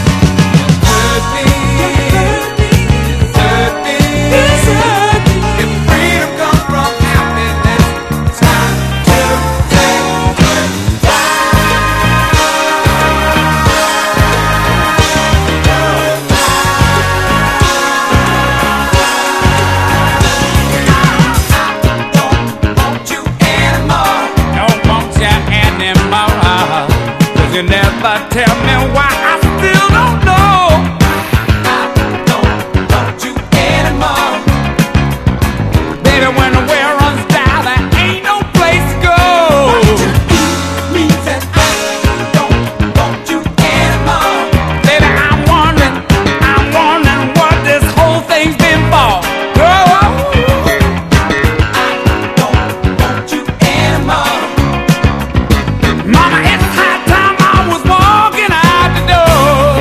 ROCK / S.S.W./A.O.R. / VOCAL / DISCO / DRUM BREAK
イントロと中盤に強力ディスコ・ブレイクを搭載した人気曲